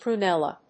発音記号
• / pruˈnɛlʌ(米国英語)
• / pru:ˈnelʌ(英国英語)